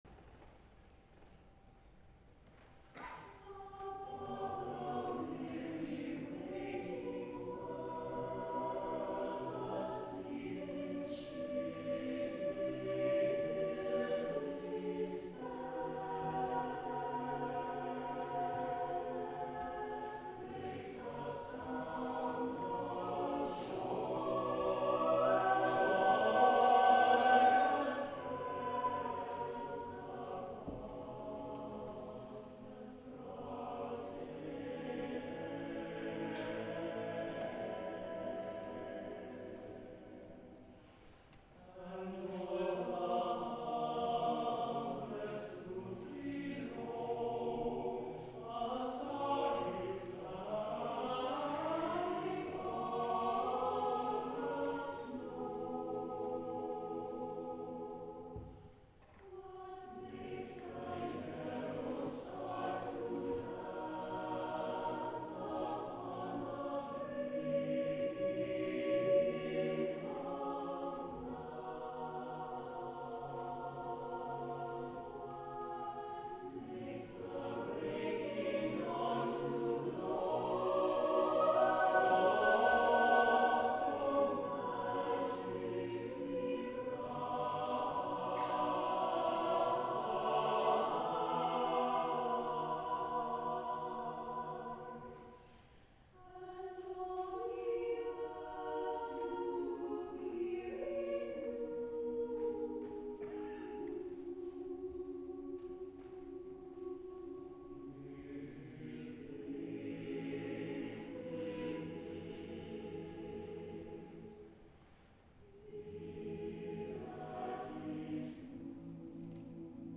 SATB a cappella